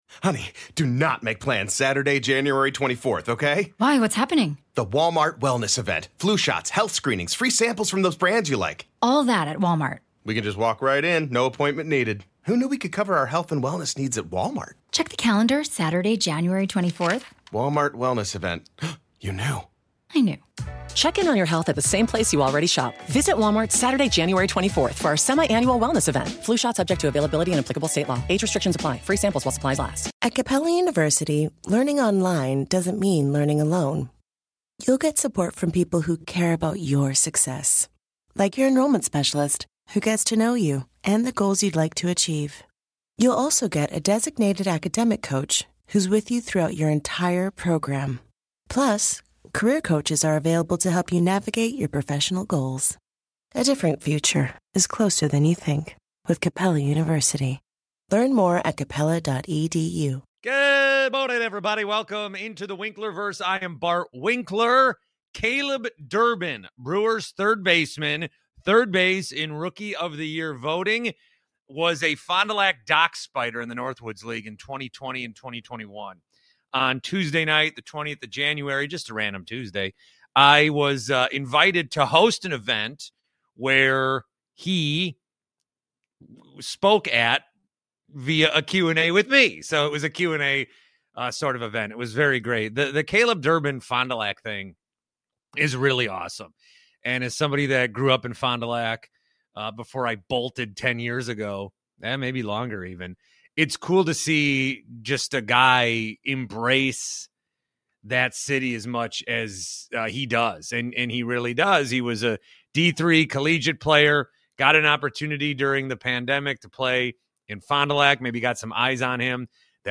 Q&A with Milwaukee Brewers 3B Caleb Durbin